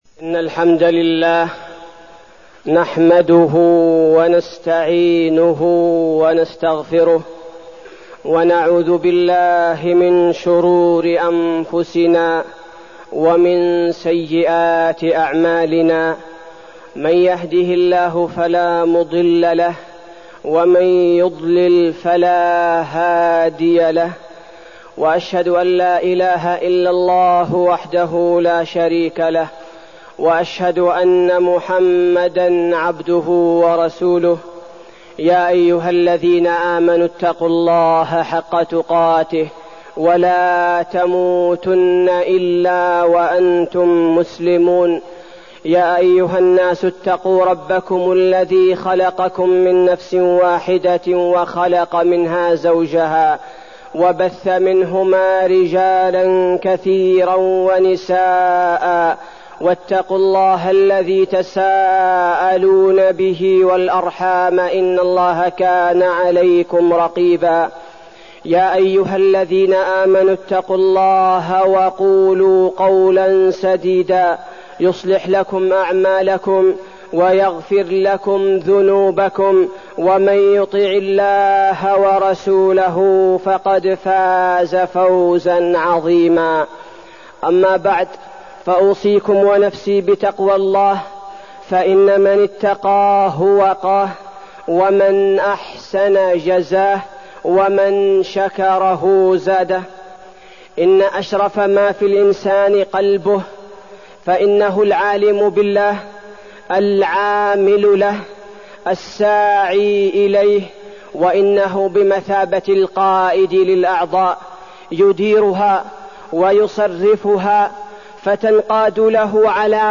تاريخ النشر ٢٦ جمادى الآخرة ١٤١٩ هـ المكان: المسجد النبوي الشيخ: فضيلة الشيخ عبدالباري الثبيتي فضيلة الشيخ عبدالباري الثبيتي القلوب